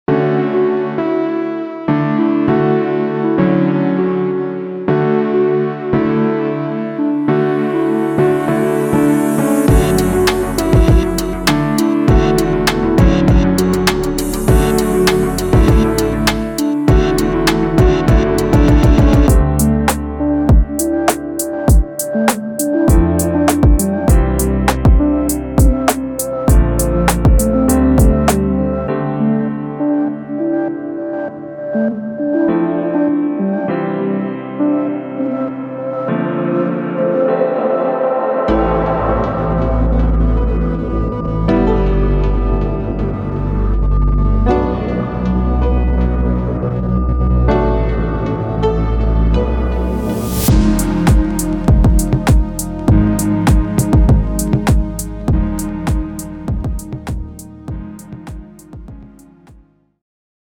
Hip-Hop
Explore Royalty Free Ambient Hip-Hop Samples
Lo-Fi Hip-Hop Beats & Loops
Think emotive chords, gliding leads and so much more.
Beautifully Processed Live Guitar & Piano Samples